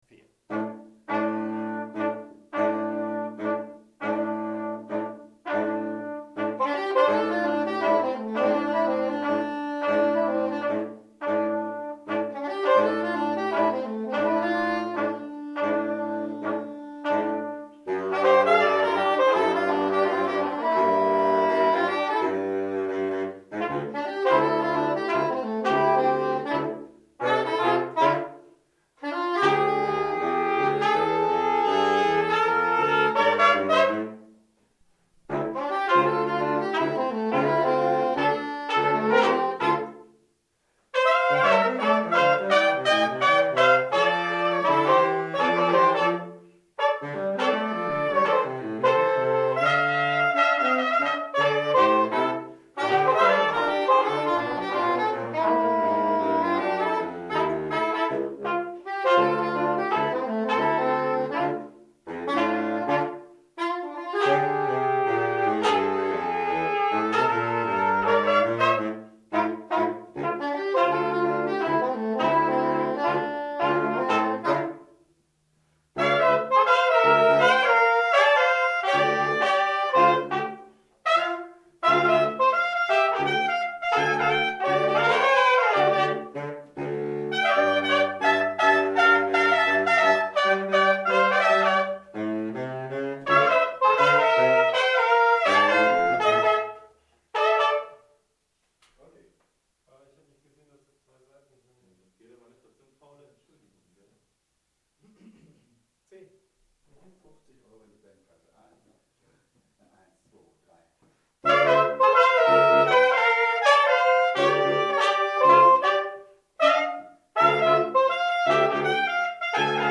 ss, bars, tp/flh, !perf
· Genre (Stil): Jazz
· Kanal-Modus: stereo · Kommentar